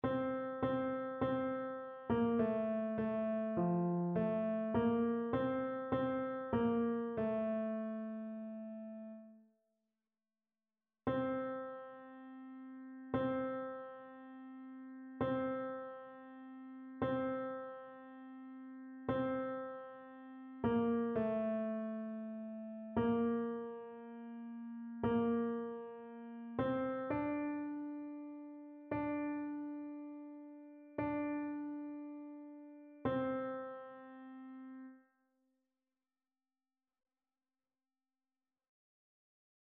annee-b-temps-de-noel-bapteme-du-seigneur-cantique-d-isaie-tenor.mp3